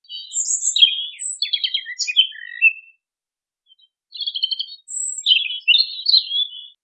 Erithacus rubecula - Robin - Pettirosso
DATE/TIME: 9/may/2004 (7 p.m.) - IDENTIFICATION AND BEHAVIOUR: the bird is hidden into a thick bush in an oak wood (Quercus pubescens, Quercus cerris). - POSITION: Poderone near Magliano in Toscana, LAT. N. 42 36' /LONG. E 11 16' - ALTITUDE: +130 m. - VOCALIZATION TYPE: spring full song. - SEX/AGE: unknown - COMMENT: notice the typical features of the robin song: all phrases in a song bout are different, the syllables which form different phrases are all different, each phrase is characterised by alternating in pitch between high and low frequencies. - MIC: (A)